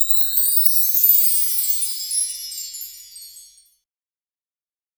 percussion 20.wav